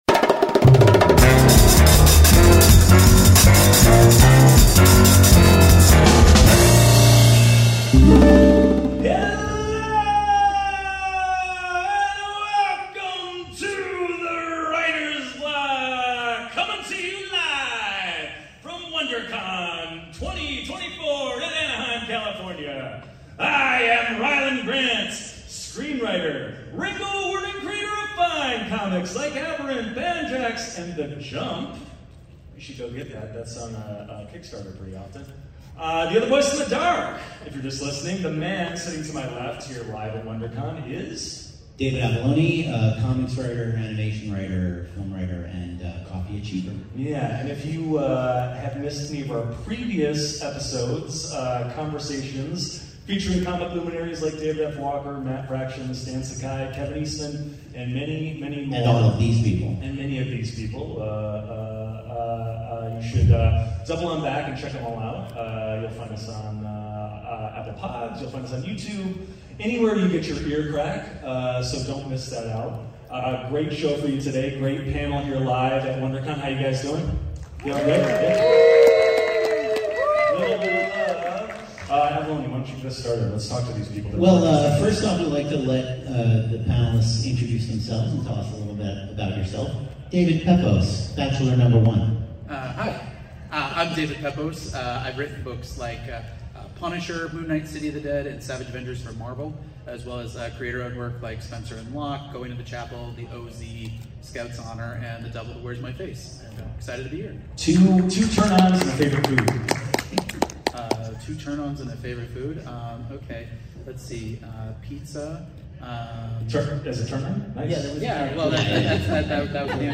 A weekly post-con-style roundtable romp with some of the most outspoken (yet still employed) creators in comics. Show biz war stories.